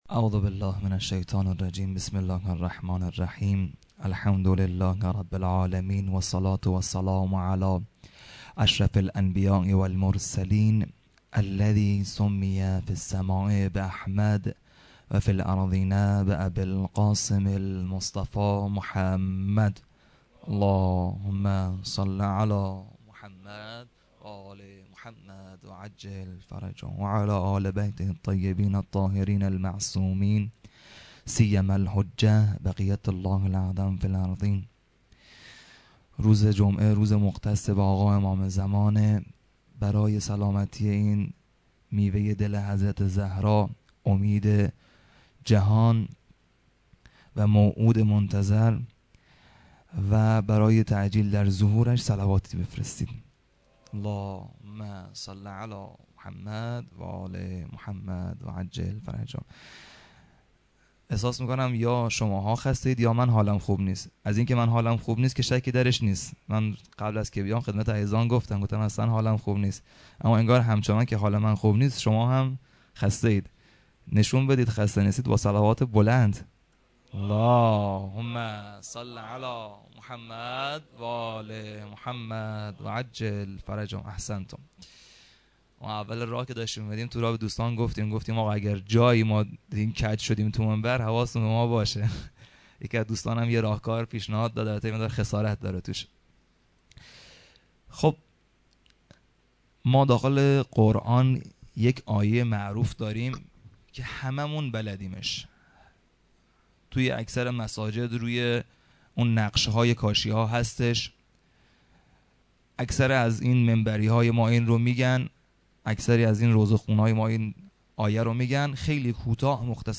shahadat-emam-javad-93-sokhanrani-2.mp3